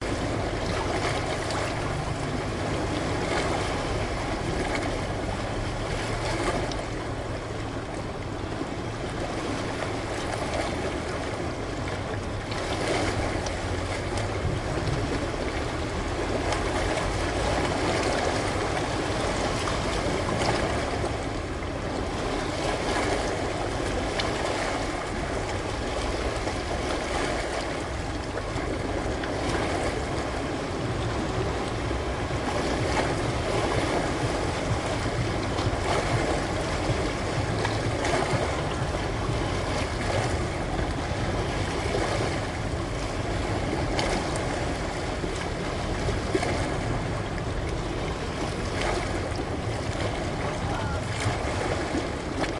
Blue sky, calm sea, no wind, finegrained sand. Original WMA sound registered with an Olympus Digital Voice Recorder WS311M. http
shore undertow beach wave
声道立体声